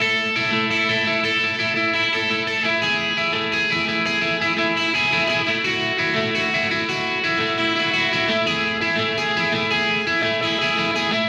Indie Pop Guitar 04.wav